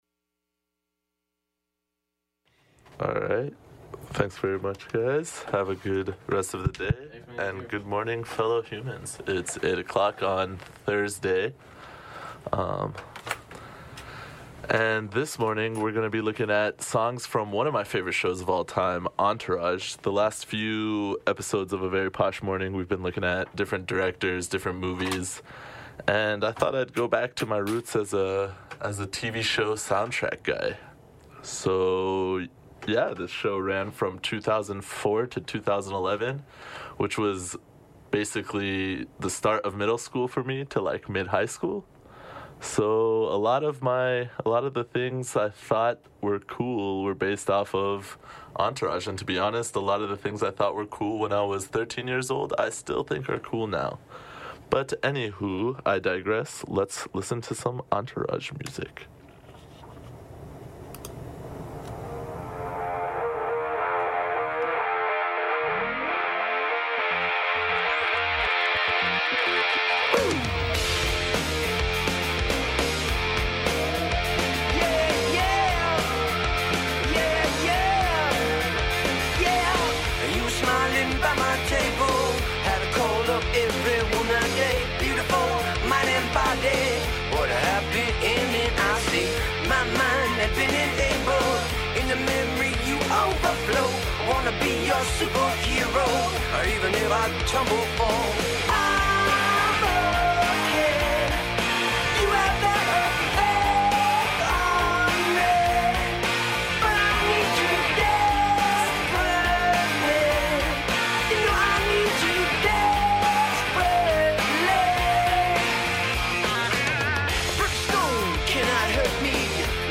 *This is a recording of a live show from the non-profit station, KXSC.